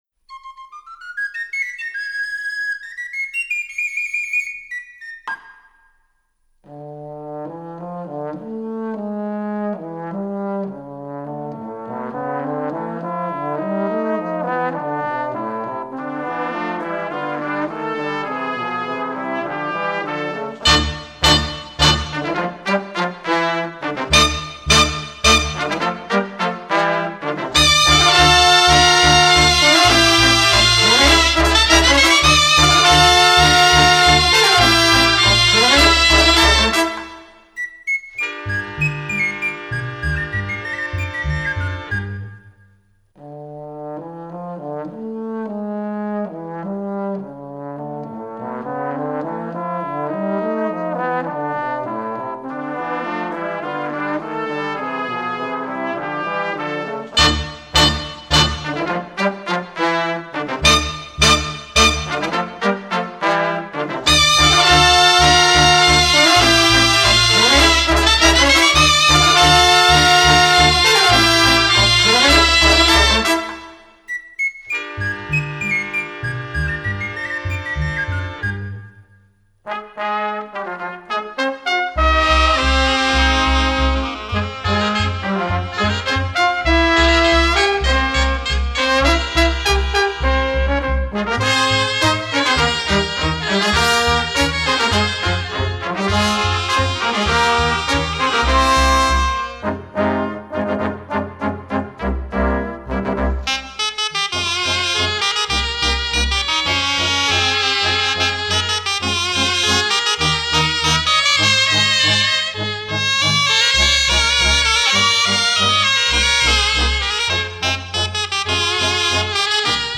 Exemple del “neo-barroc”